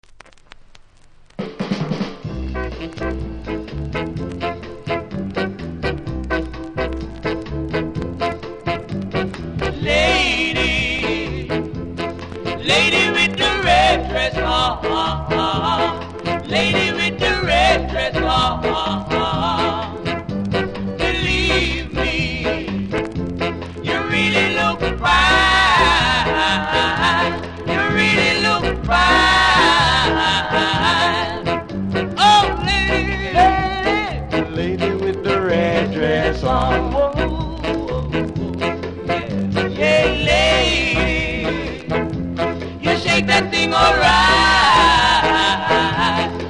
そこそこキズありノイズもありますので試聴で確認下さい。